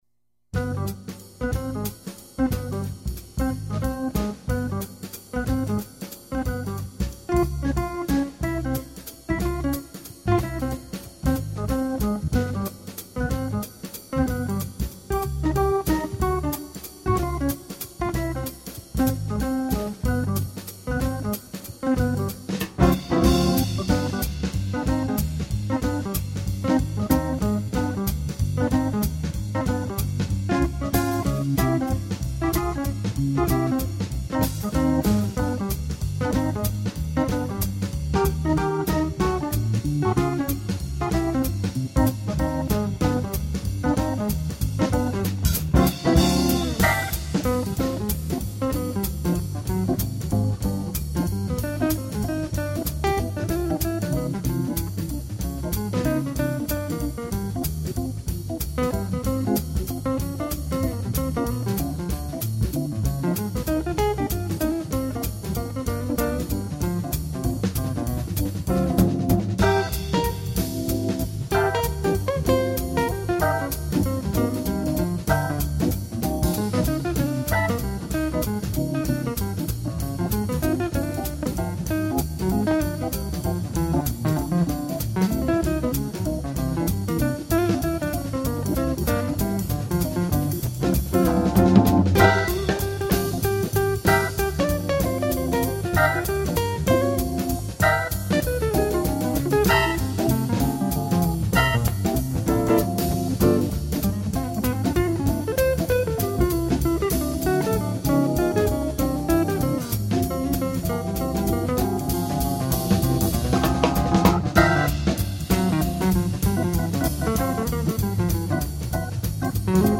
Jazz Guitar
organist